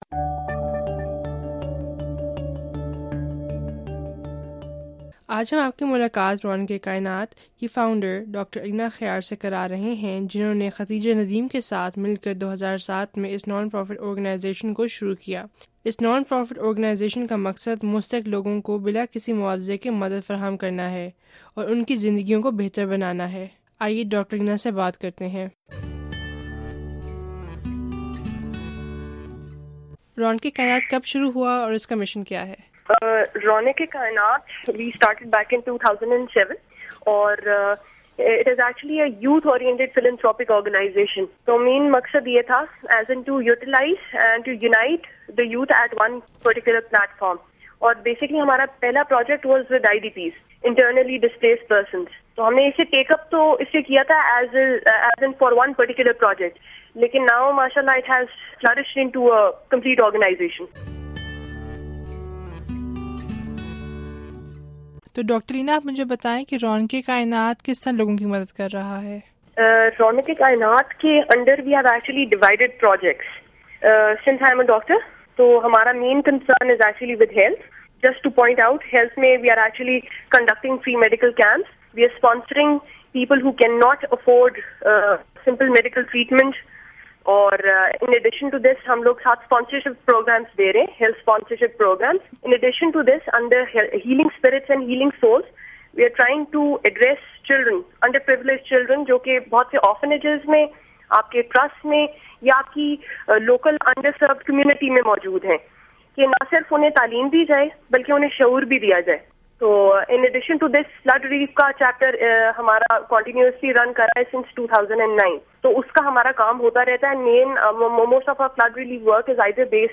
بات چیت